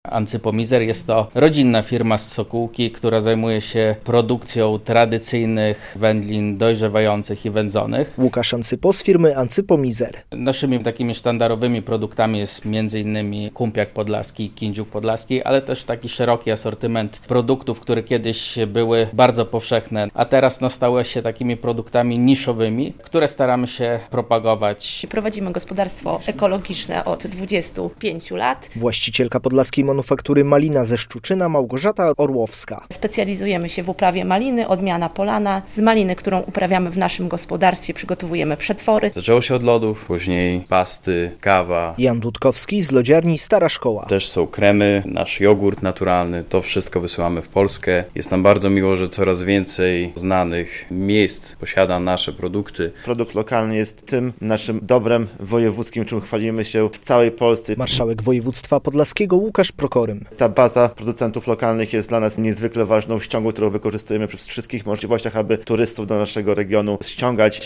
Nowe twarze kampanii promocyjnej - relacja